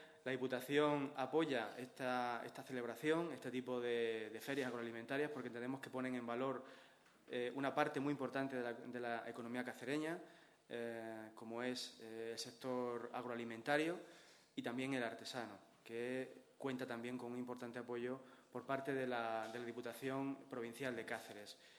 CORTES DE VOZ
20/10/2015.- El sector agroalimentario y artesano como señas de identidad de los pueblos cacereños, es lo que ha destacado el diputado de Desarrollo y Turismo Sostenible de la Diputación, Fernando Grande Cano, durante la presentación de la II Feria Agroalimentaria de Santiago del Campo, que se celebrará el próximo sábado 24 de octubre.